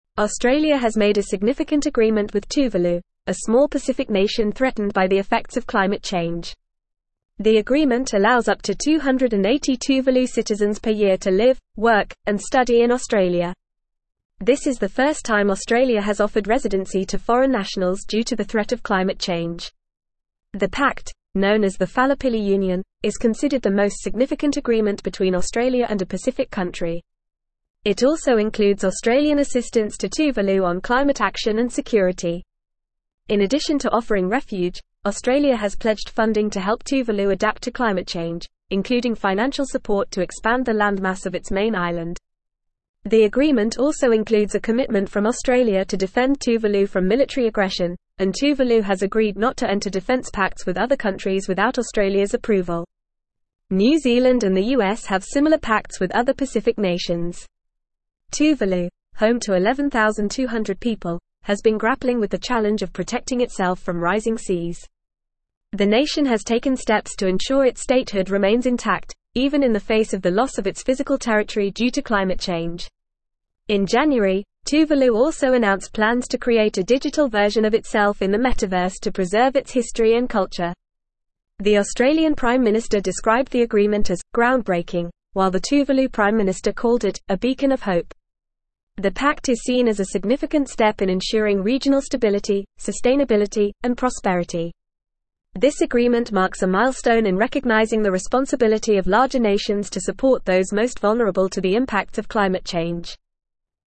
Fast
English-Newsroom-Advanced-FAST-Reading-Australias-Historic-Pact-Refuge-for-Tuvaluans-from-Climate-Change.mp3